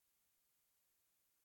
silent.ogg